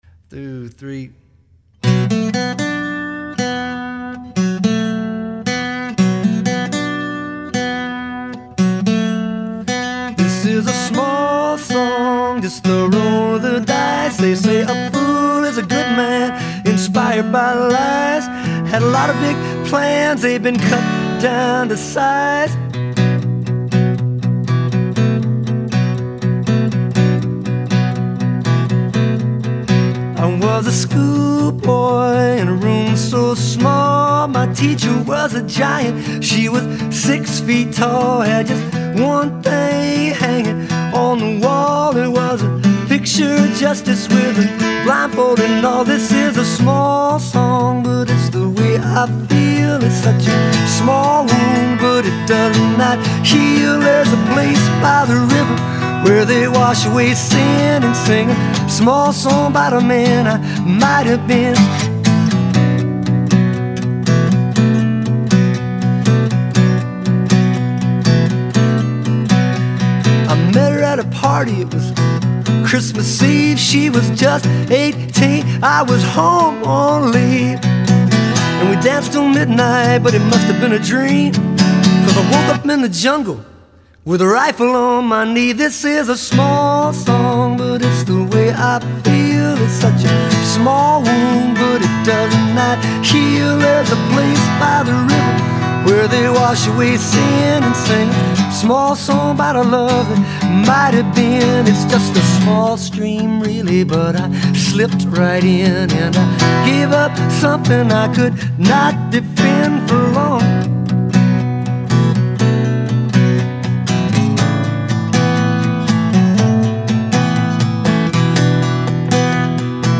Nashville-based singer/songwriter
acoustic guitar